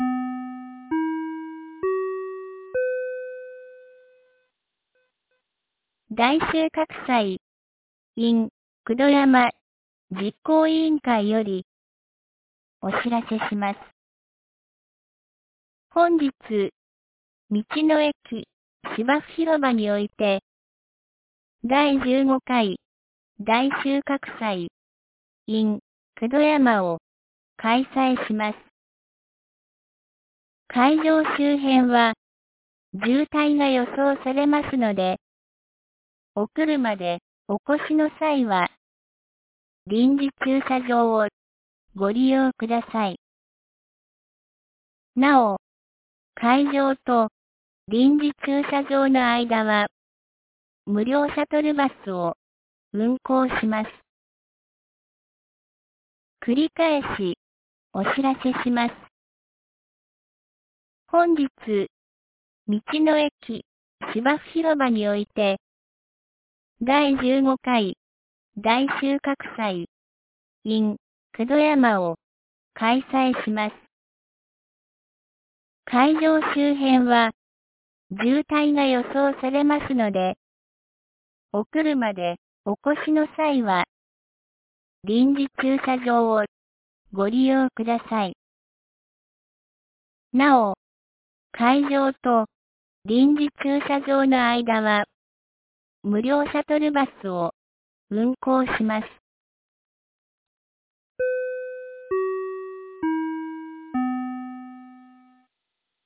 2023年11月11日 09時01分に、九度山町より全地区へ放送がありました。
放送音声